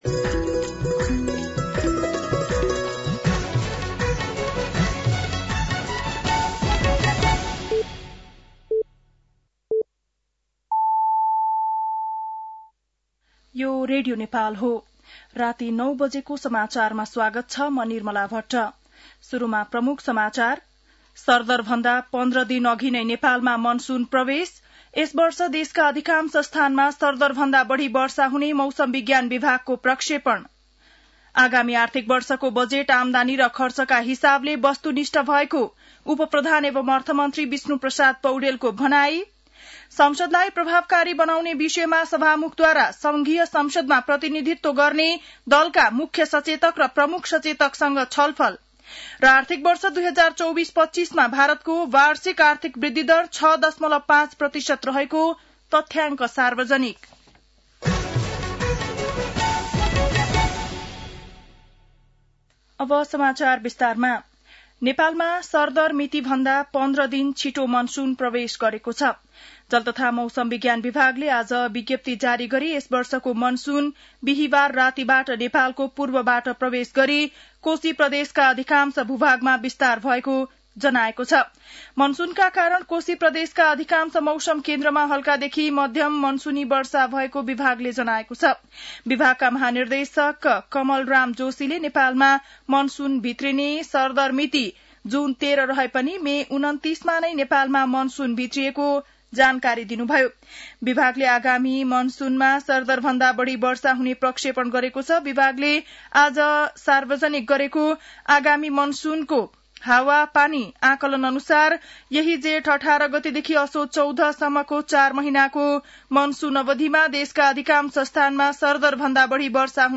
बेलुकी ९ बजेको नेपाली समाचार : १६ जेठ , २०८२
9-PM-Nepali-NEWS-2-16.mp3